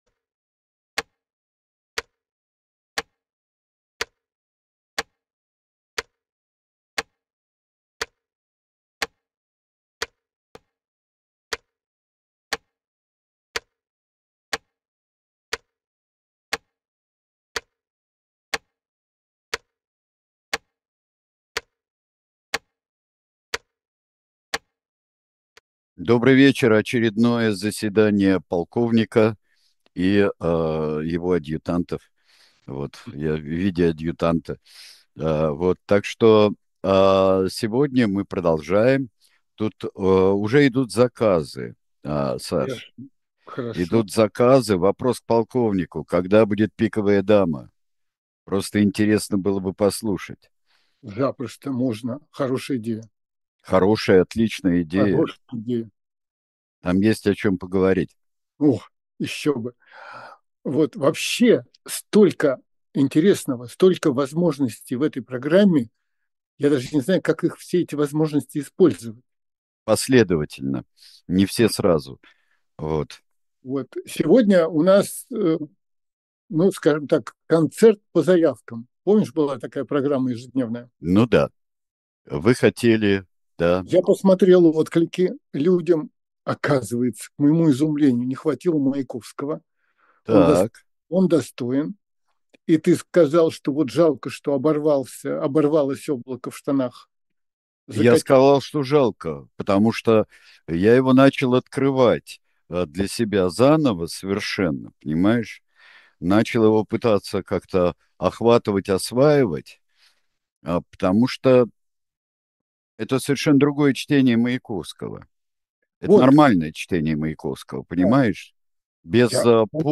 Эфир ведут Александр Минкин и Сергей Бунтман